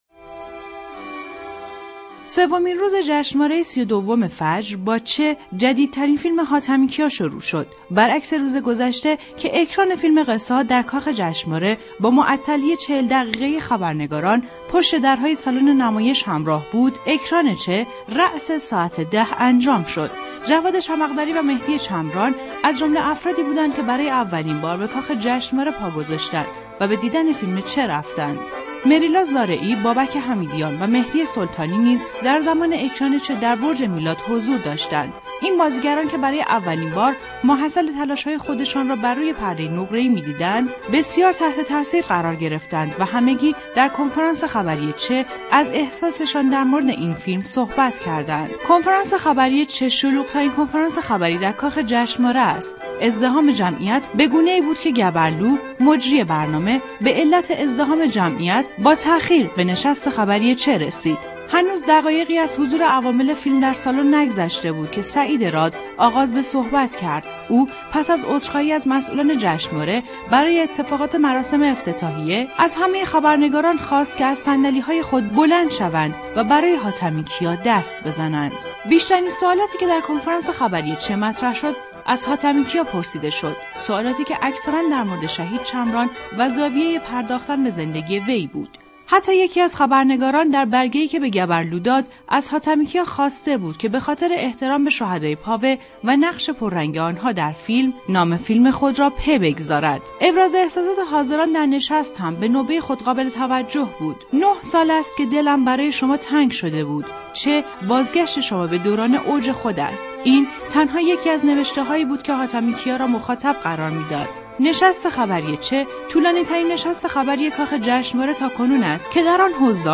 گزارش صوتی تسنیم از سومین روز جشنواره بین المللی فیلم فجر